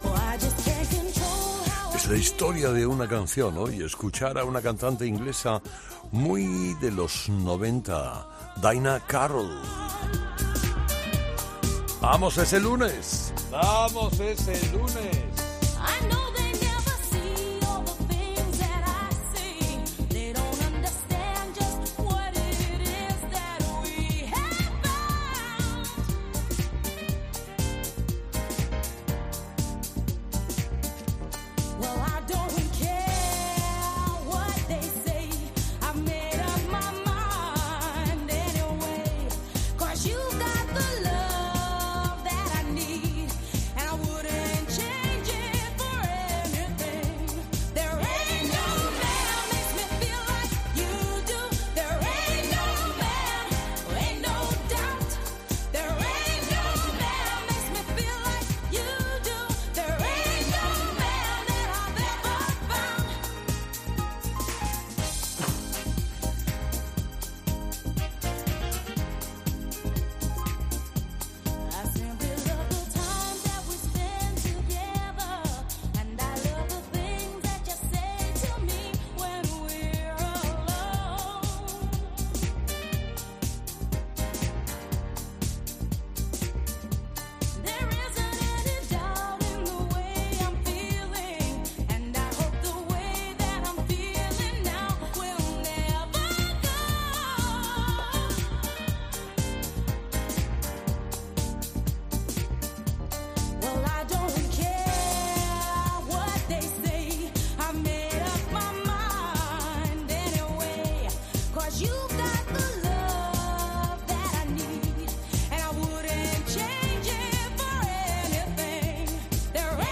Escuchar a una cantante inglesa muy de los 90